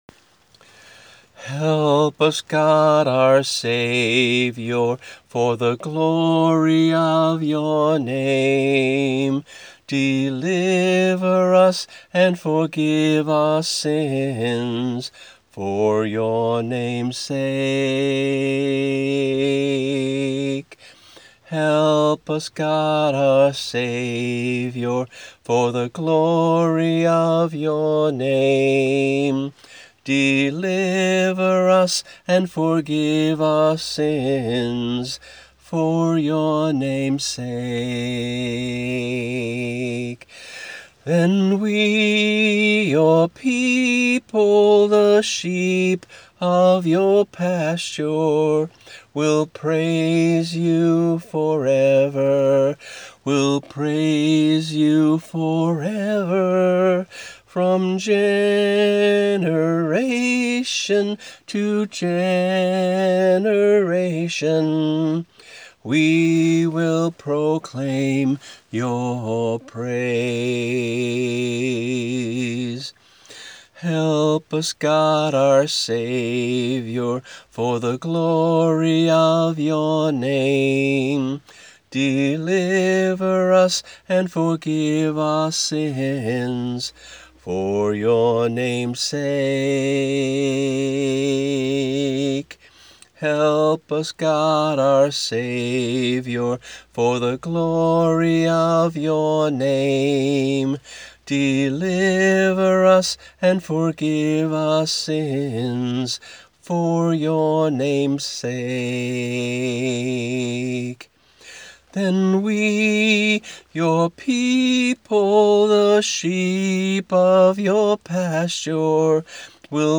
voice only